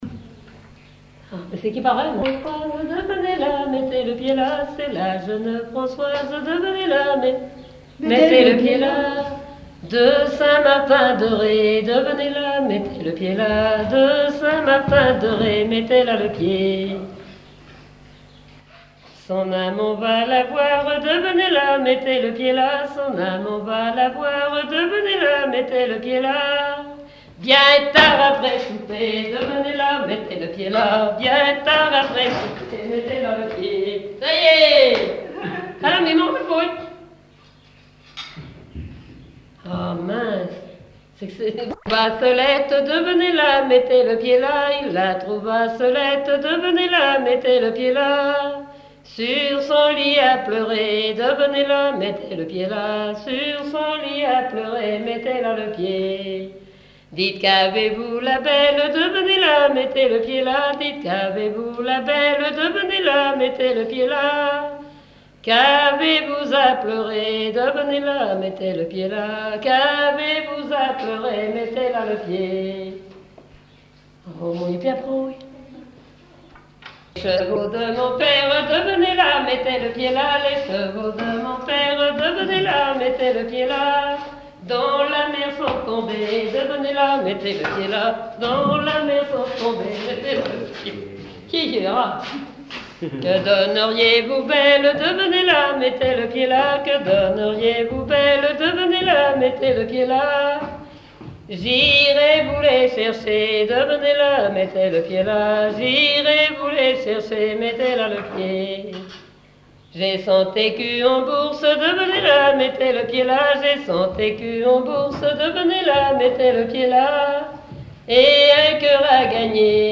Fonction d'après l'analyste danse : ronde ;
Genre laisse
Catégorie Pièce musicale inédite